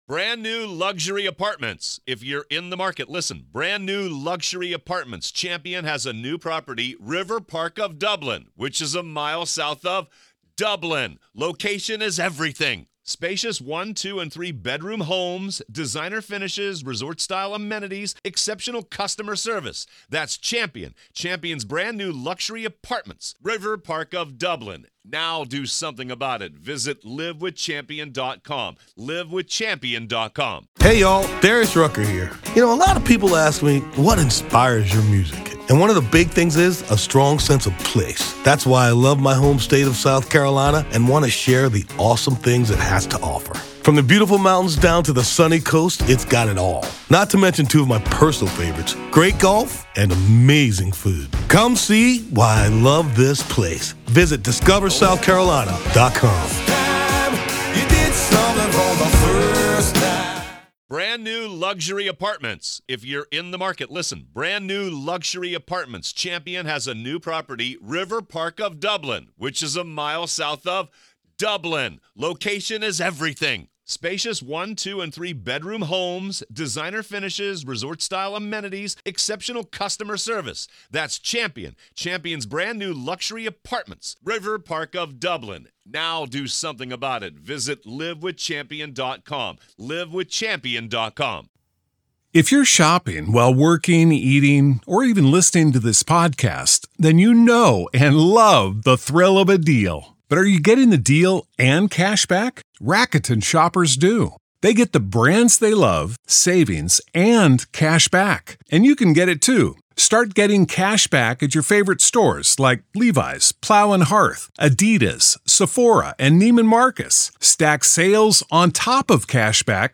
COMMONWEALTH CLOSING ARGUMENTS PART 2: The Trial of Karen Read: Boyfriend Cop Murder Trial – MA v. Karen Read
Welcome to a special episode of "The Trial of Karen Read," where today, we find ourselves inside the courtroom of the case against Karen Read.